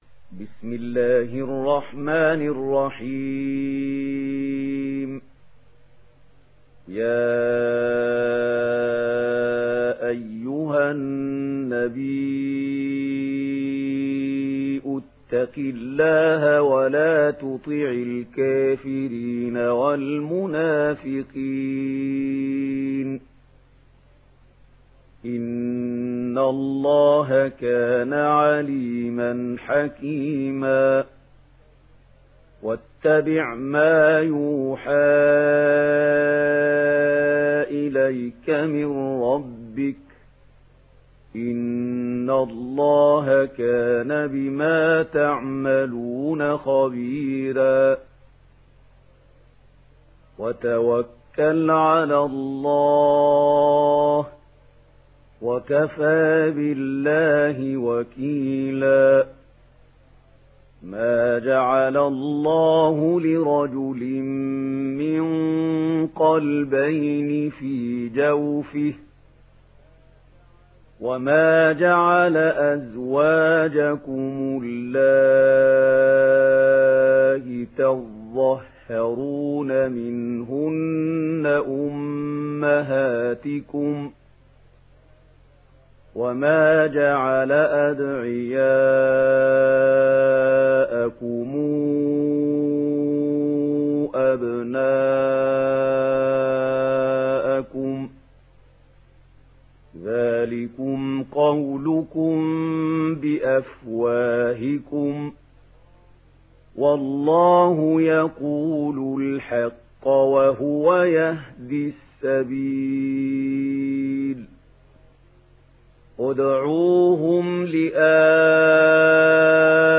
دانلود سوره الأحزاب mp3 محمود خليل الحصري روایت ورش از نافع, قرآن را دانلود کنید و گوش کن mp3 ، لینک مستقیم کامل